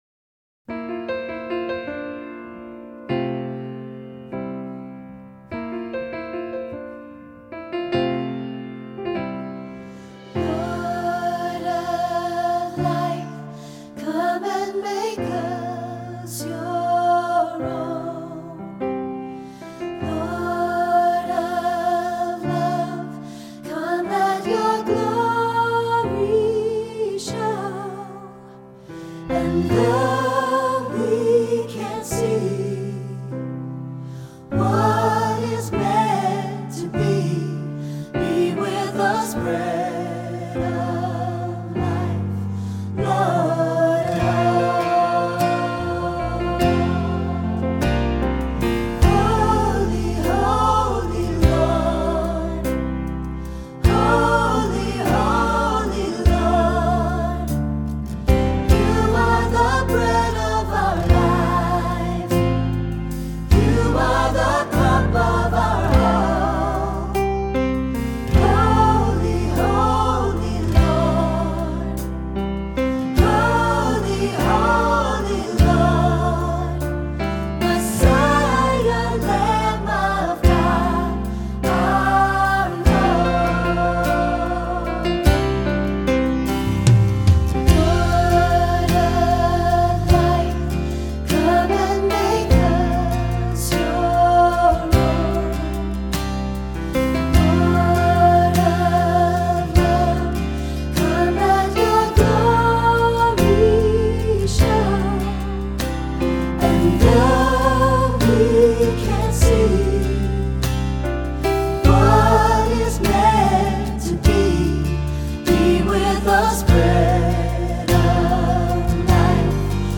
Voicing: Three-part choir; Cantor; Assembly